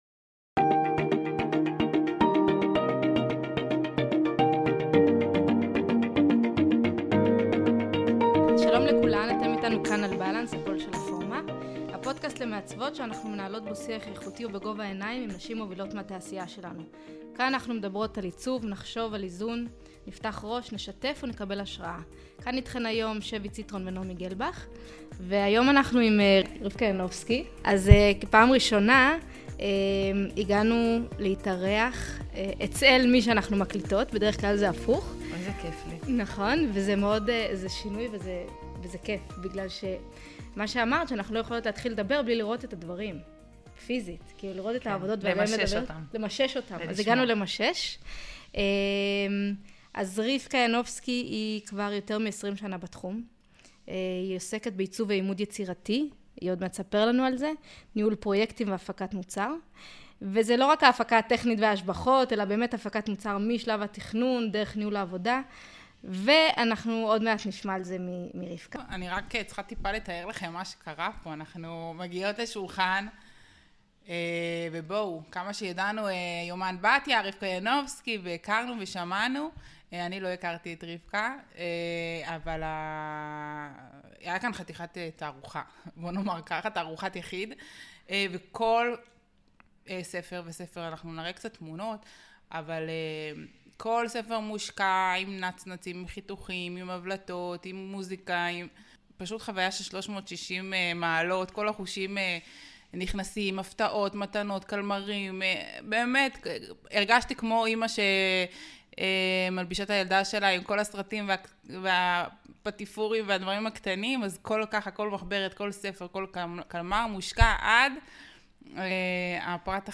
איך מצליחים לחדש, לגרום לריגוש ולהשתמש בטכנולוגיה חדשה בלי לעורר ביקורת ופרובוקציה. דיברנו על אינטואציות פנימיות, מה קורה לאגו שלנו בעבודת צוות, איך היא מנהלת משברים, מה קורה אצלה באיזון בין הבית לעבודה ולמה היא קוראת למעצבות צעירות להשתפשף אצל מעצבות ותיקות יותר. שיחה כנה שמציפה התלבטויות ותובנות שנאספו בעשרים שנה של נסיון מקצועי.